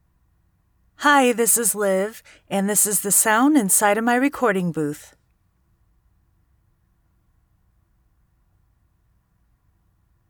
Booth: Fully enclosed and treated from top-to-bottom with 2" thick acoustic foam paneling Microphones: Sennheiser MKH 416 shotgun condenser and Audio-Technica AT 2035 cardioid condenser Interface: Focusrite Scarlett with pre-amp
Booth sound sample